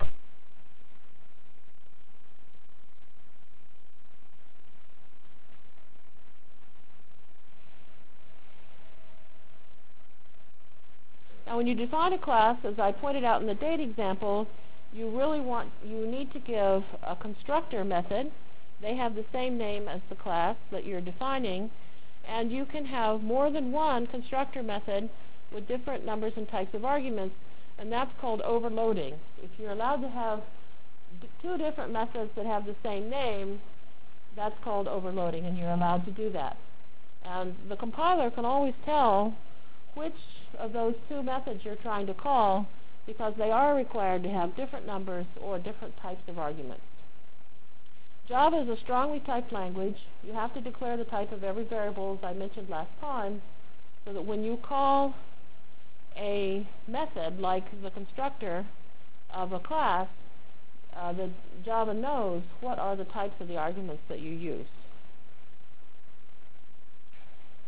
From Jan 27 Delivered Lecture for Course CPS616 -- Java Lecture 2 -- Basic Applets and Objects CPS616 spring 1997 -- Jan 27 1997.